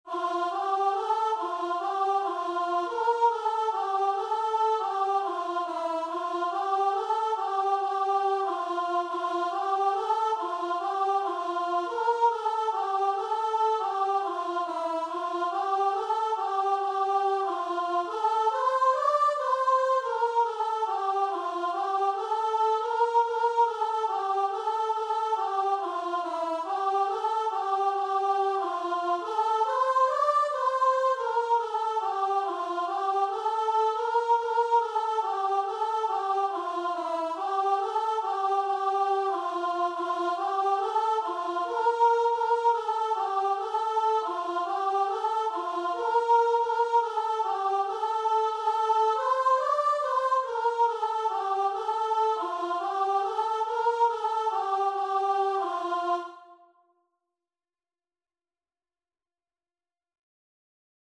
Free Sheet music for Voice
C major (Sounding Pitch) (View more C major Music for Voice )
4/4 (View more 4/4 Music)
E5-D6
Christian (View more Christian Voice Music)